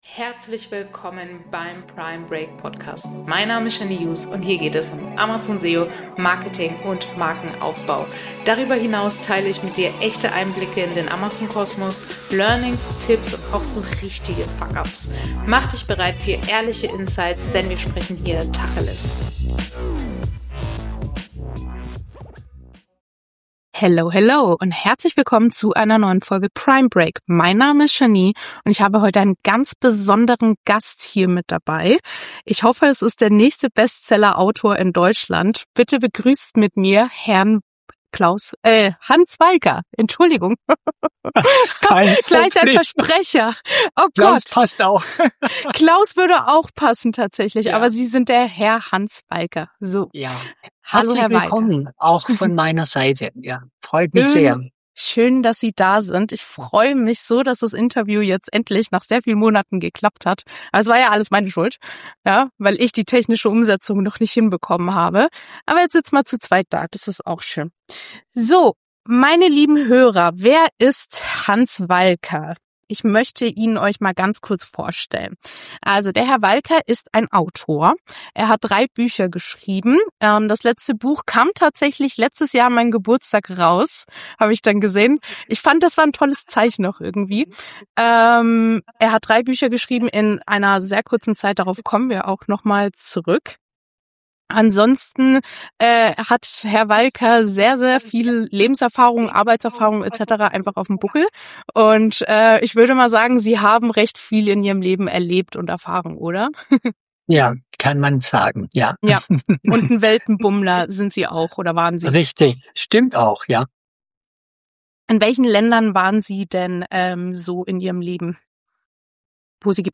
Beschreibung vor 3 Tagen om Schreiben zum Verkaufen: In dieser Folge spreche ich mit einem Autor, der eine komplette Trilogie veröffentlicht hat – und plötzlich vor der Herausforderung stand, seine Bücher nicht nur zu schreiben, sondern auch zu vermarkten. Wir sprechen über seinen Weg vom ersten Manuskript bis zur Veröffentlichung, Zweifel auf dem Weg und warum Lesungen für ihn zum Gamechanger wurden. Außerdem bekommst du spannende Einblicke, wie Bücher auf Amazon zum „Produkt“ werden – und warum die richtige Präsentation dabei eine entscheidende Rolle spielt.